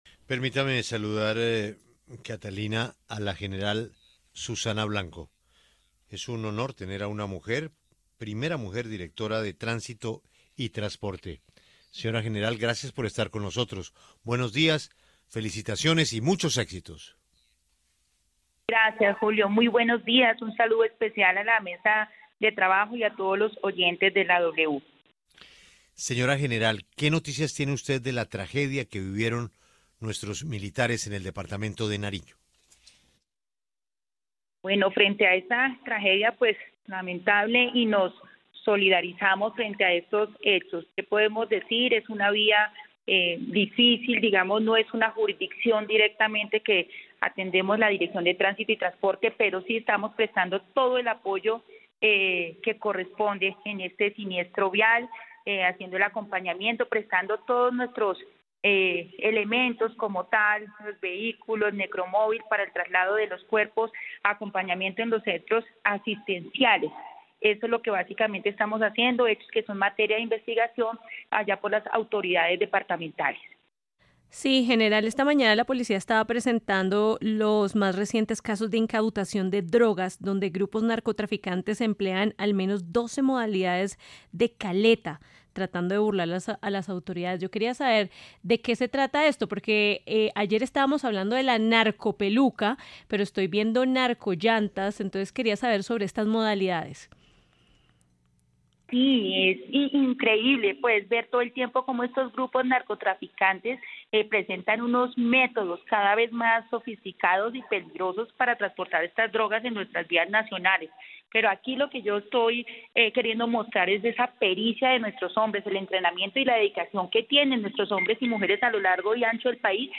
La general Claudia Blanco, directora de Tránsito y Transporte de la Policía Nacional, se refirió a las acciones adoptadas en la lucha contra el narcotráfico en Colombia.